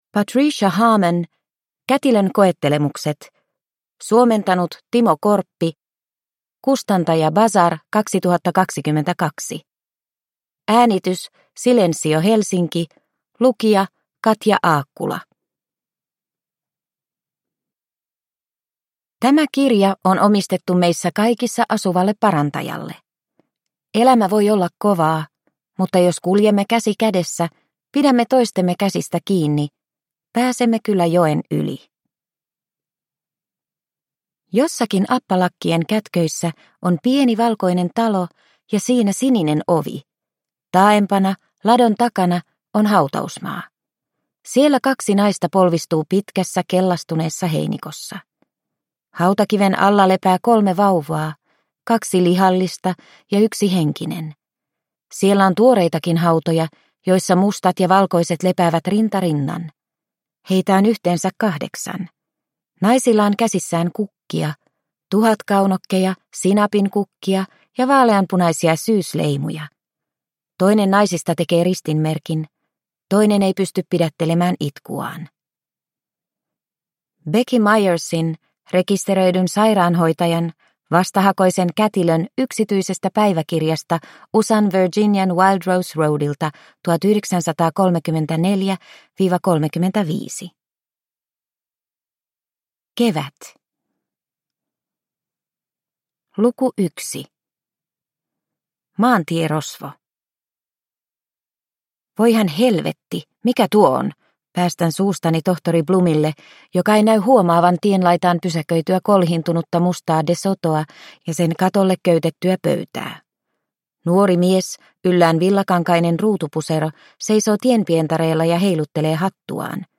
Kätilön koettelemukset – Ljudbok – Laddas ner